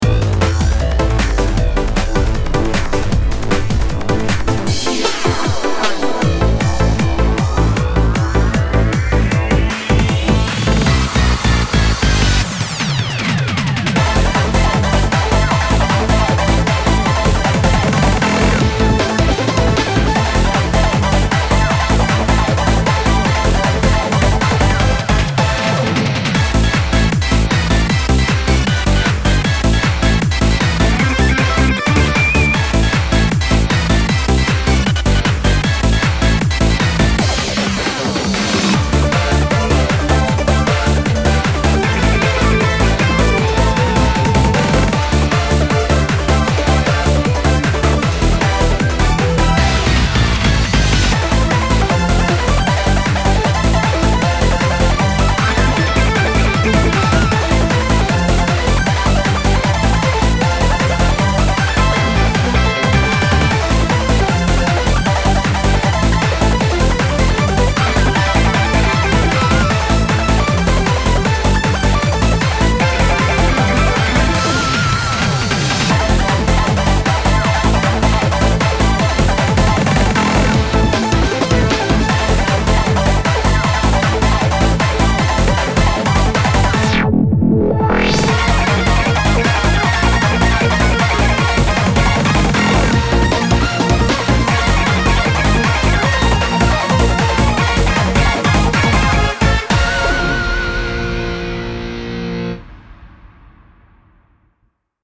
BPM155
Audio QualityPerfect (High Quality)
Time to go EUROBEAT!